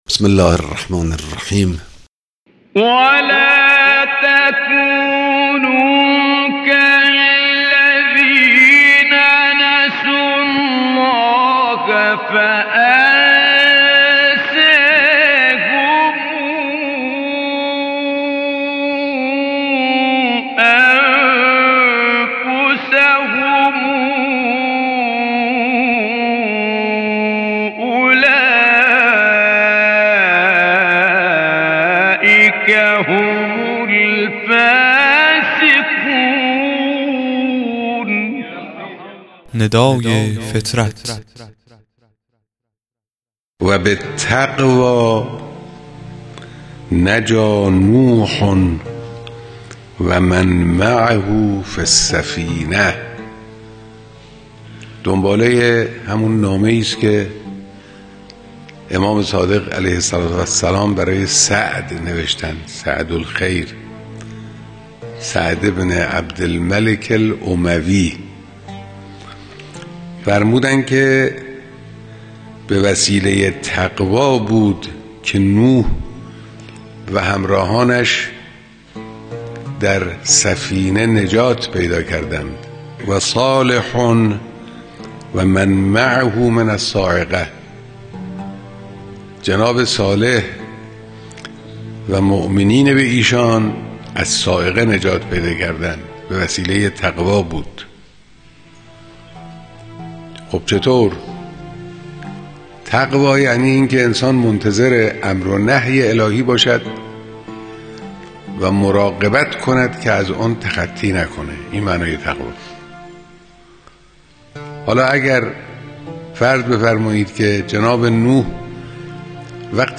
قطعه صوتی کوتاه و زیبا از امام خامنه ای